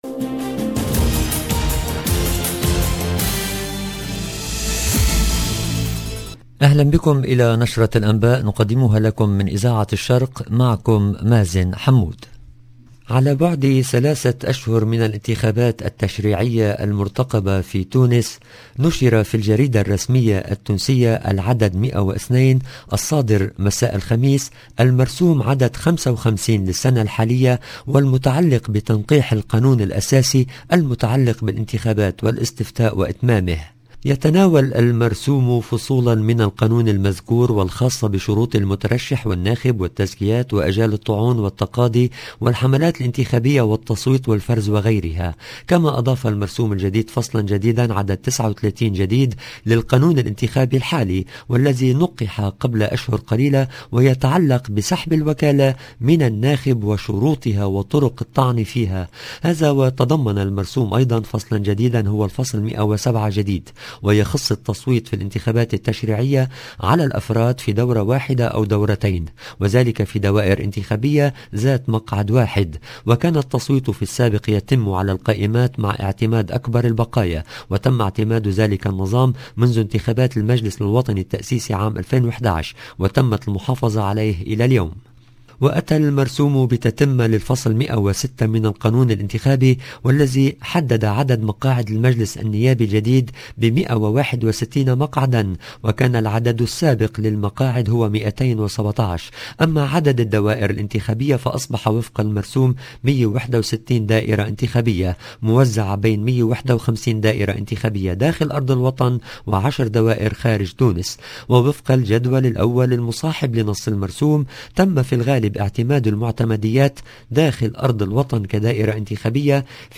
EDITION DU JOURNAL DU SOIR EN LANGUE ARABE DU 16/9/2022